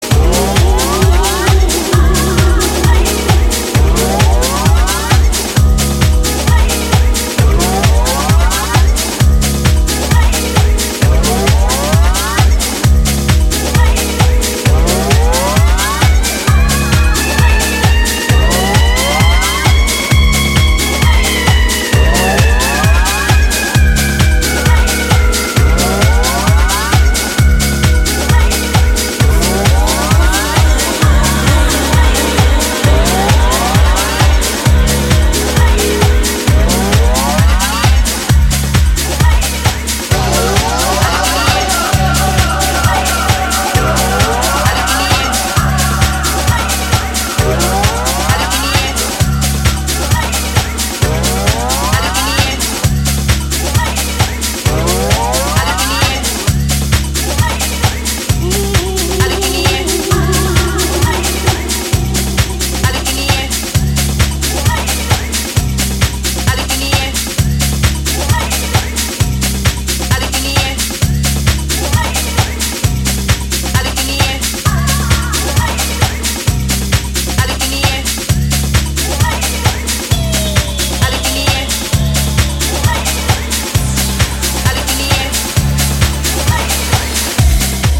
執拗なディスコサンプルの反復とエフェクトの応酬で畳み掛ける、BPM130前後のキラーチューン全4曲を収録。